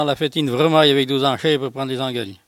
Patois - archive